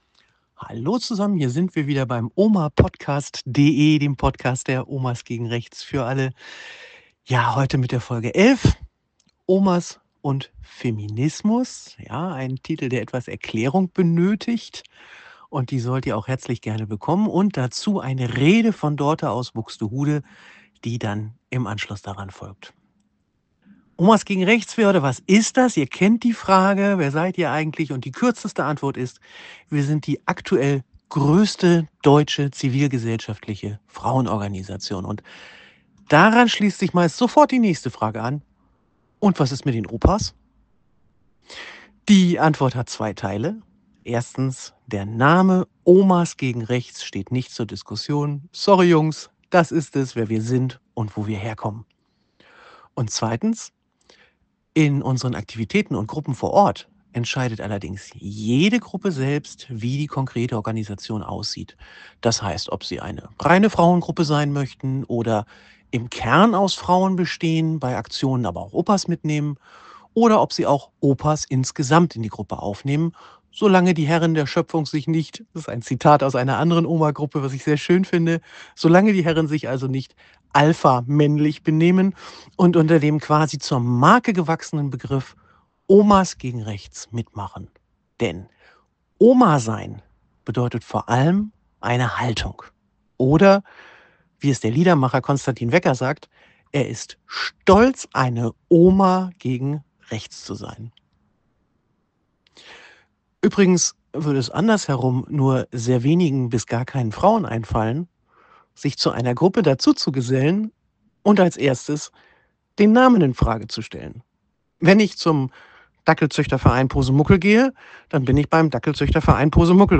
#25-005) OMAS und Feminismus / Rede aus Buxtehude